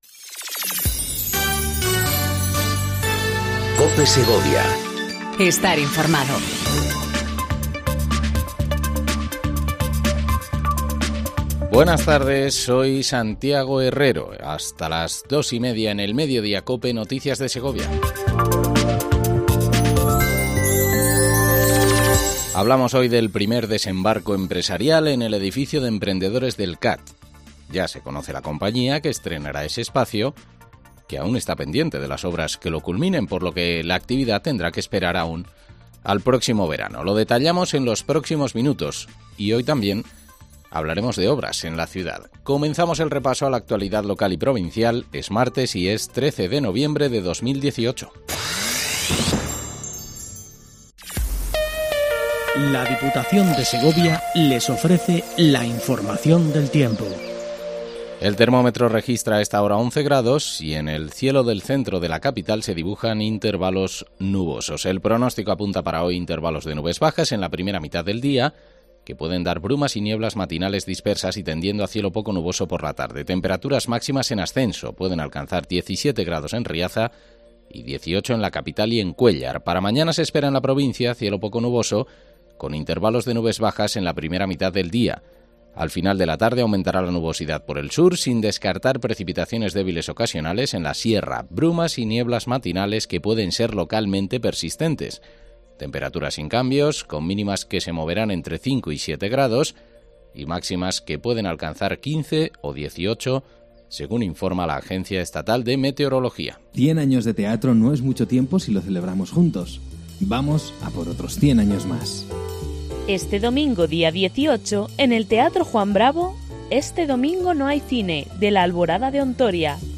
INFORMATIVO MEDIODÍA COPE SEGOVIA 14:20 DEL 13/11/18